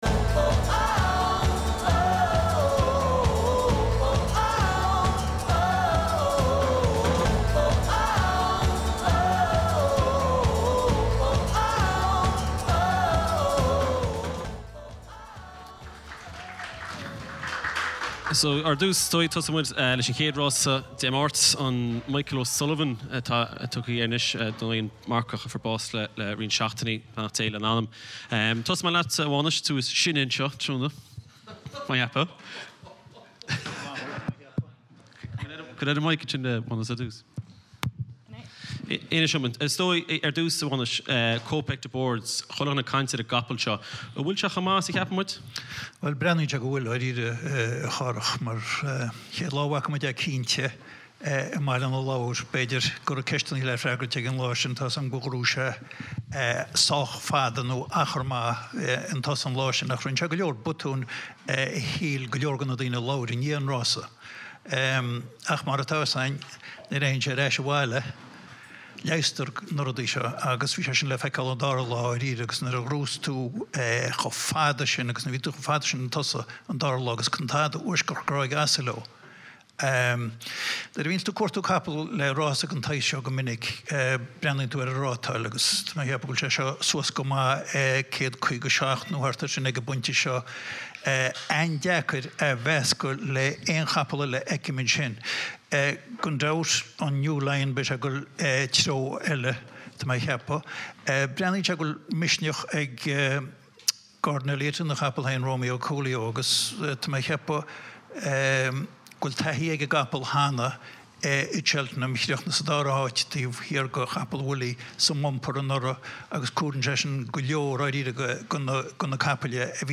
Bhí oíche den scoth againn ag an seó beo a bhí ag an Spota Dubh thar an deireadh seachtaine agus muid ag breathnú ar Fhéile Rásaíochta Cheltenham.